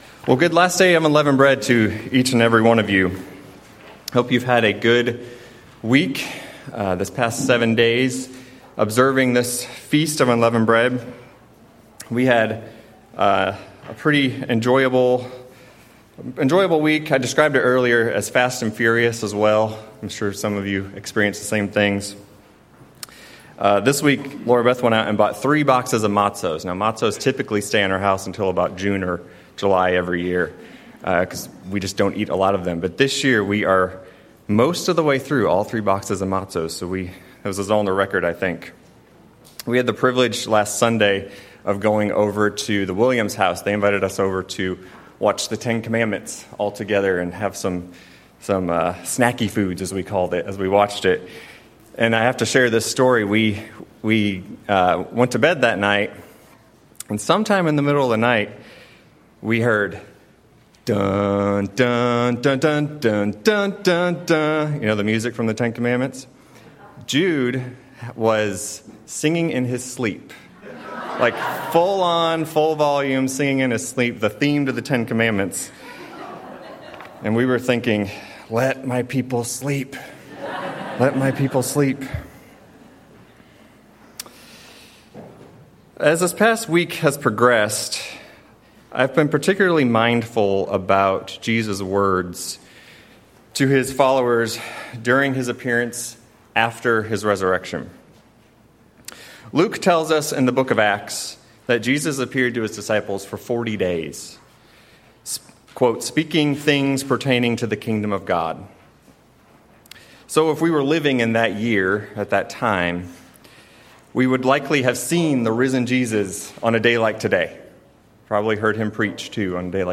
Sermons
Given in Nashville, TN Murfreesboro, TN